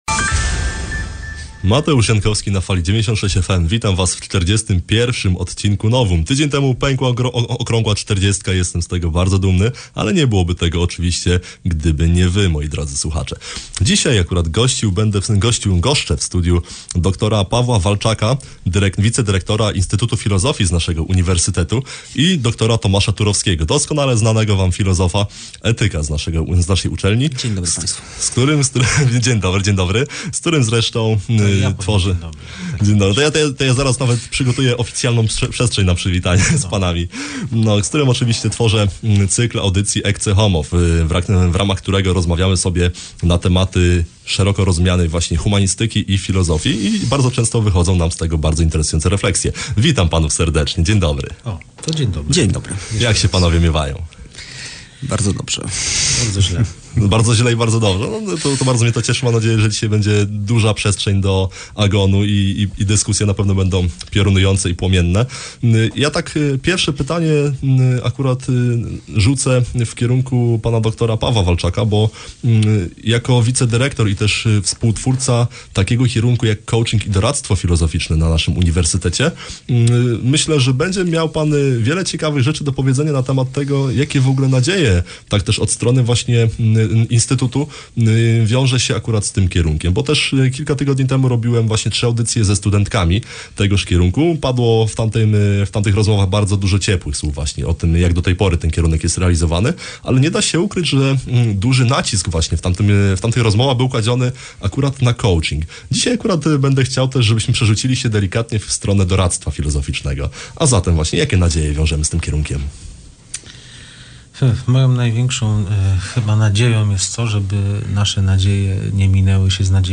Łapcie nagranie i delektujcie się rozwojem projektu „Ecce homo”, tj. rozmów o zacięciu humanistyczno-filozoficznym.